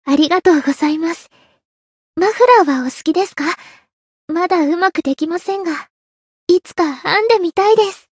灵魂潮汐-安德莉亚-圣诞节（送礼语音）.ogg